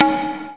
normal-hitwhistle2.wav